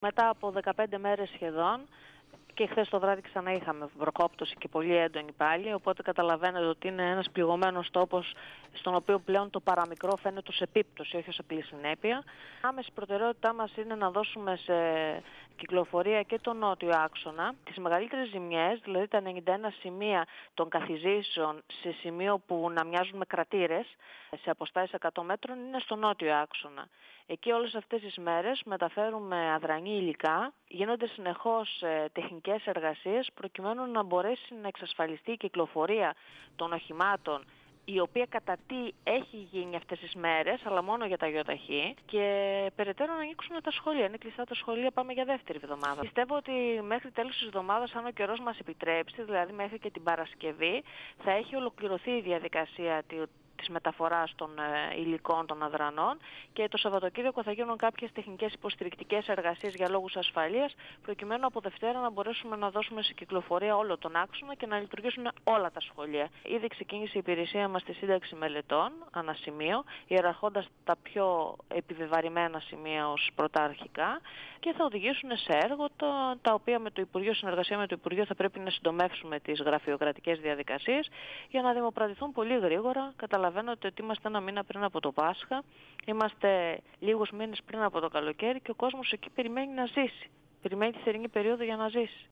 H αντιπεριφερειάρχης Μαγνησίας, Δωροθέα Κολυνδρίνη, στον 102FM του Ρ.Σ.Μ. της ΕΡΤ3
Συνέντευξη